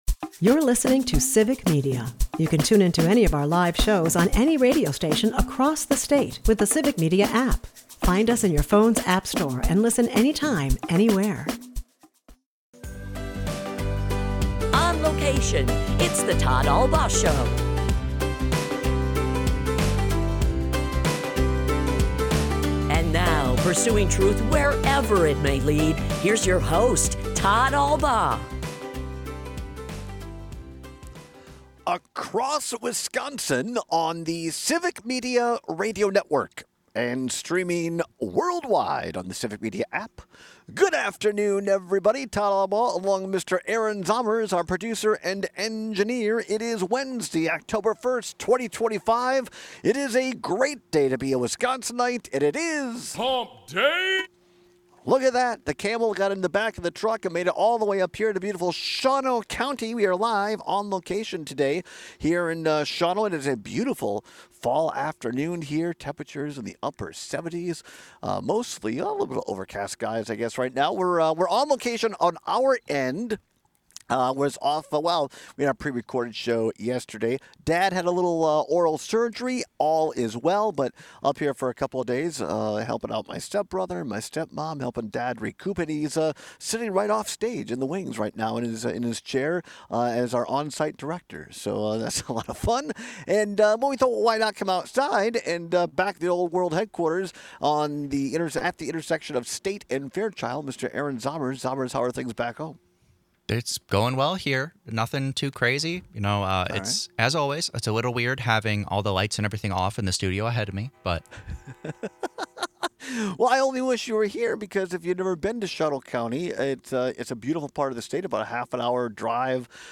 We share some audio from both of them, and it’s just as bizarre as we expected.